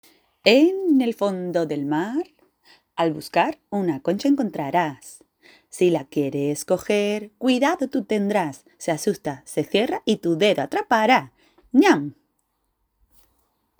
Doble.